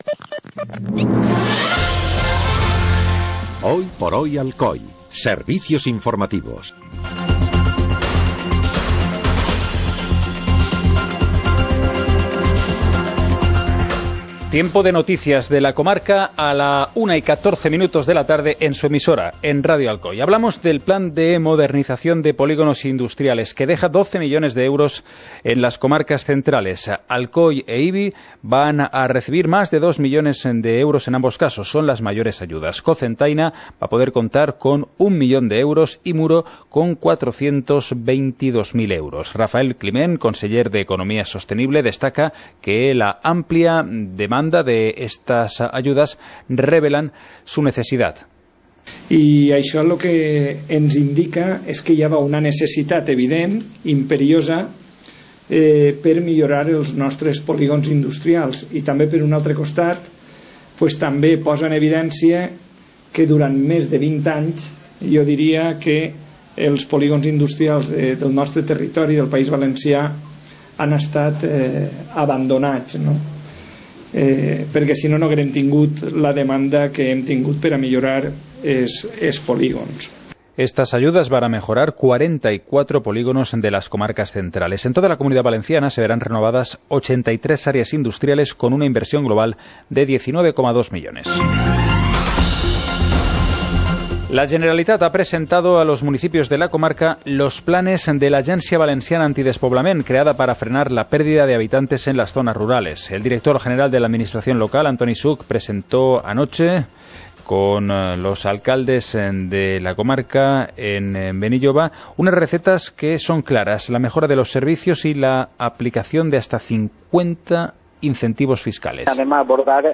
Informativo comarcal - miércoles, 14 de junio de 2017